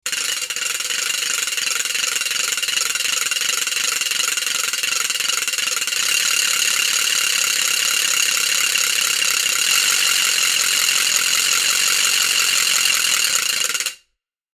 RAGLJA-VELIKA.mp3